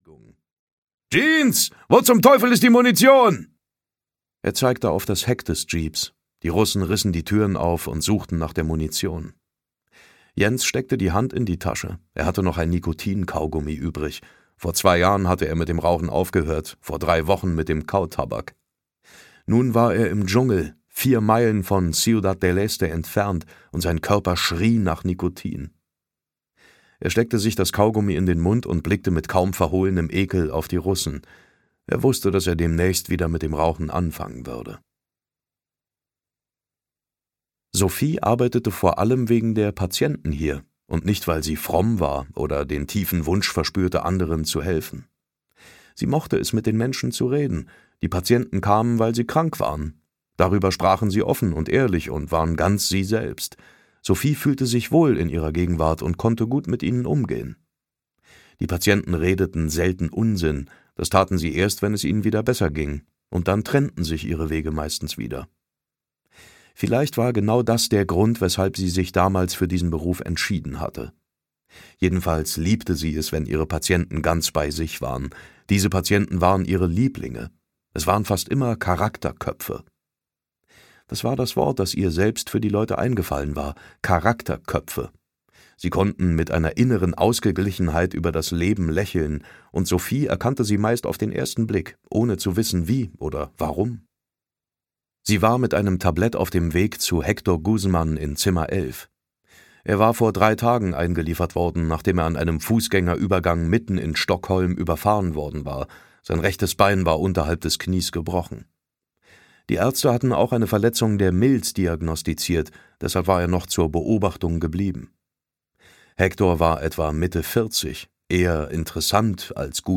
Unbescholten (Die Sophie-Brinkmann-Trilogie 1) - Alexander Söderberg - Hörbuch